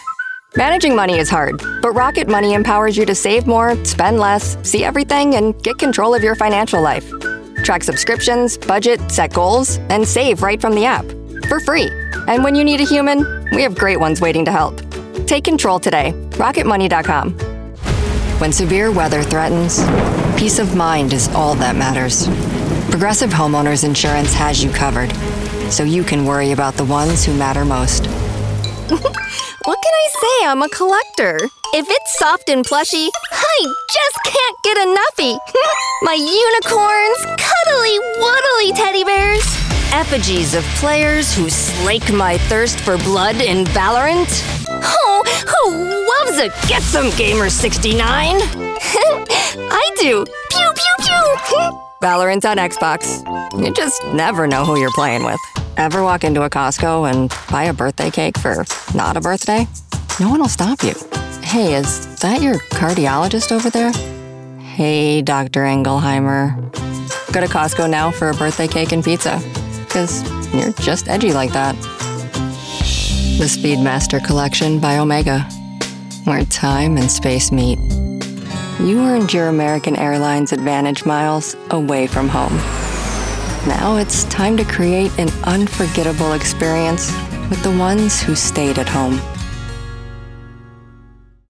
2025Demo2.mp3